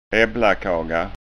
SKÅNSKA UTTAL